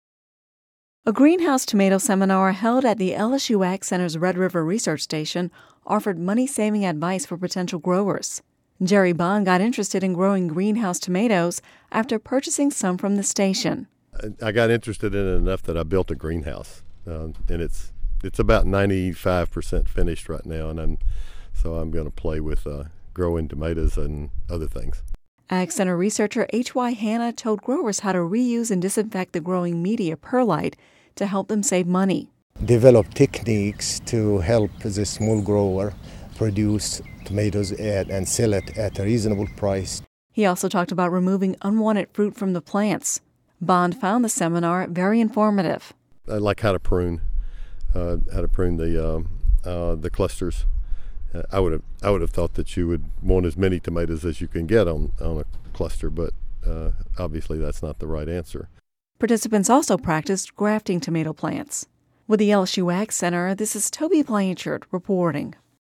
(Radio News 03/14/11) A greenhouse tomato seminar held at the LSU AgCenter’s Red River Research Station offered money-saving advice for growers interested in producing greenhouse tomatoes.